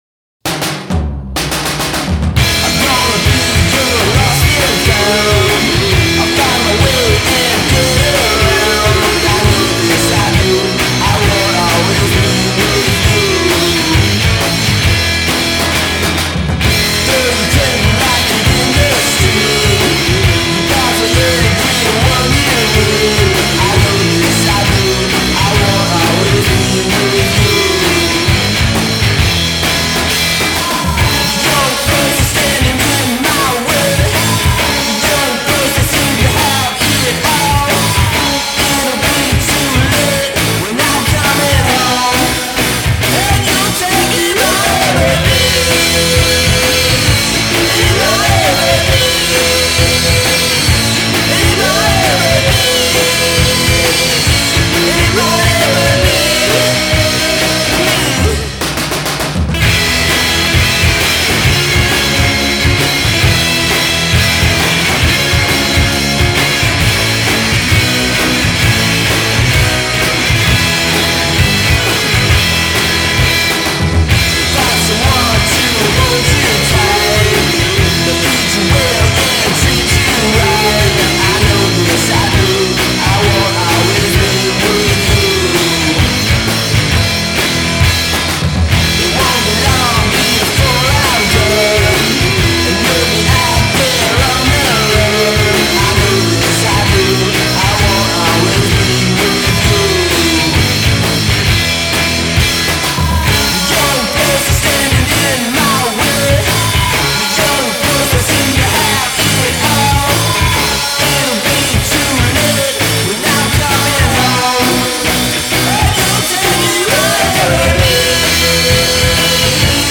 accelerated chillwave